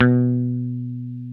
Index of /90_sSampleCDs/Roland L-CD701/GTR_Dan Electro/BS _Dan-O Bass